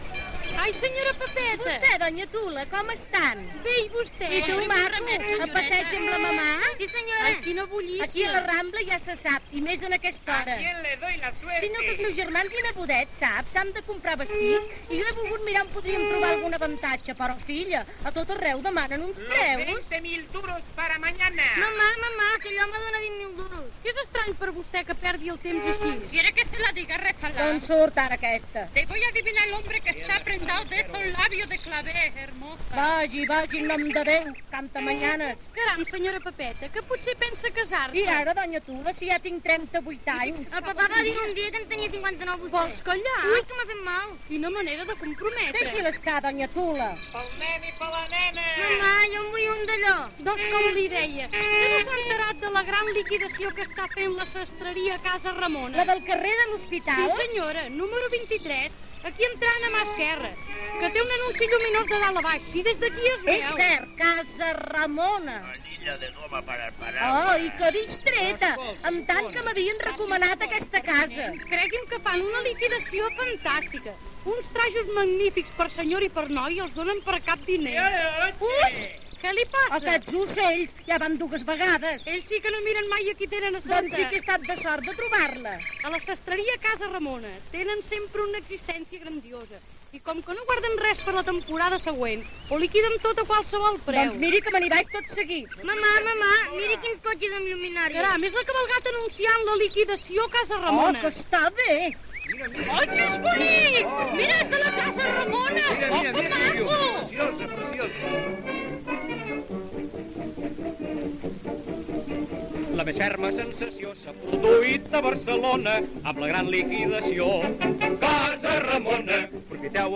Publicitat publicada en disc de pedra a l'any 1932.